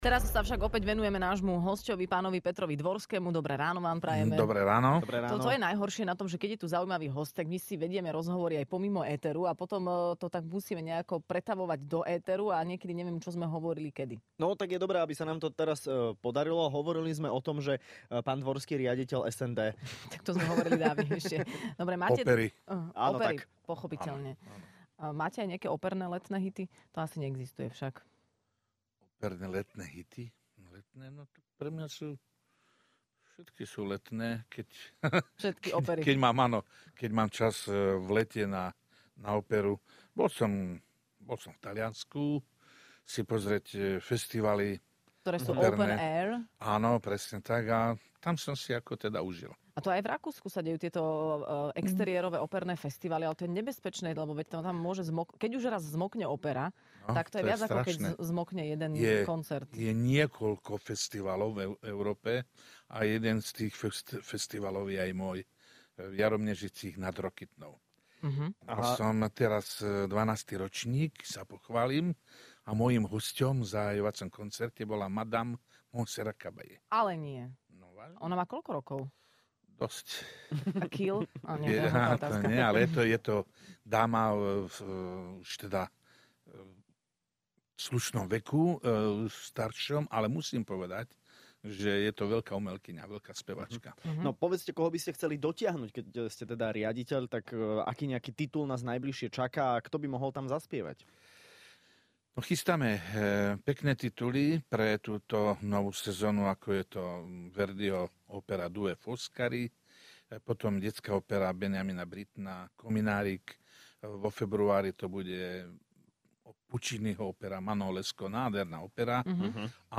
Hosťom v Rannej šou bol spevák Peter Dvorský.